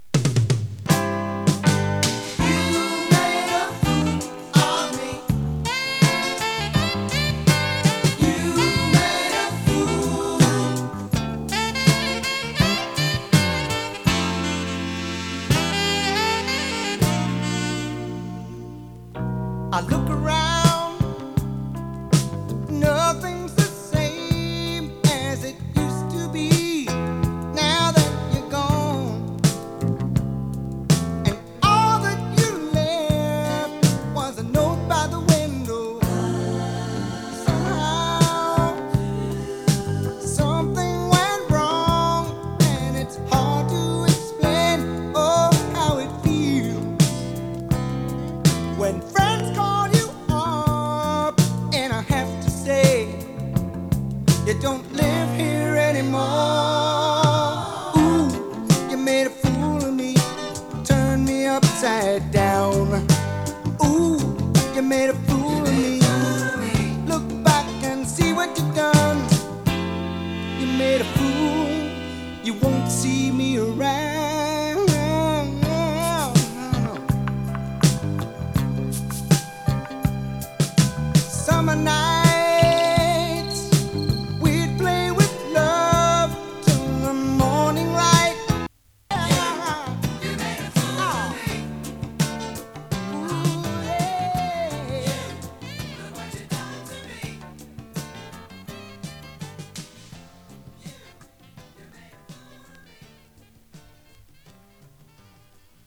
ブル－アイドソウル
更に哀愁を増した
＊音の薄い部分で時折パチ・ノイズ。